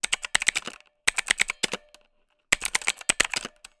Keyboard, computer, mechanical, typing, quickly, rapidly, keys, press, button, click, tap_96Khz_Mono_ZoomH4n_NT5-004
button click computer key keyboard keypress press sound effect free sound royalty free Sound Effects